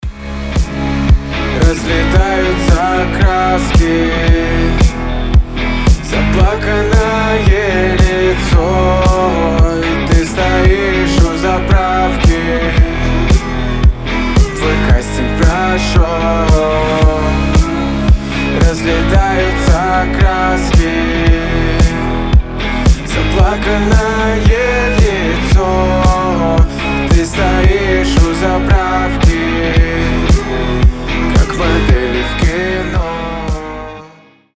• Качество: 320, Stereo
гитара
мужской голос
Pop Rock
indie rock
легкий рок
акустика